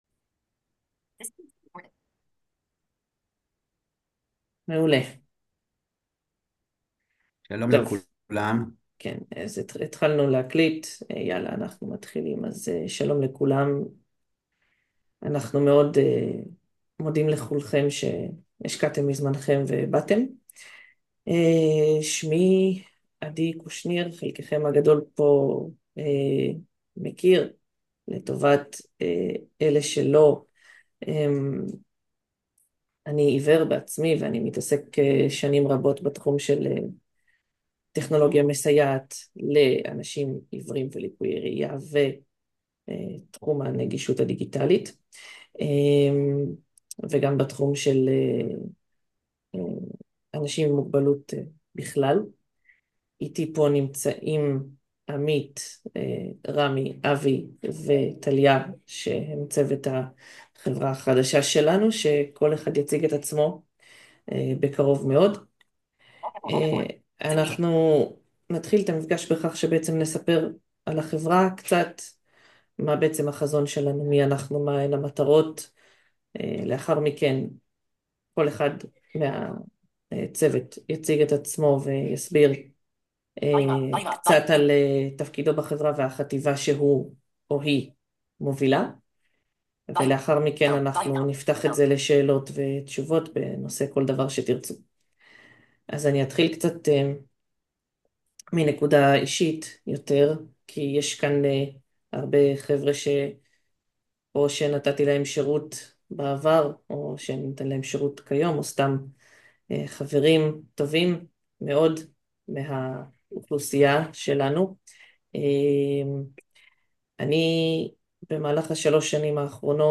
לקוחות ושותפים יקרים, ביום ג', 18.3.2025, התקיים מפגש ההשקה הרשמי של החברה שלנו ב-Zoom. במפגש הצגנו את פעילות החברה, חשפנו אתכם לשתי החטיבות השונות, הכרנו לכם את כל הצוות, וענינו על שאלות חשובות.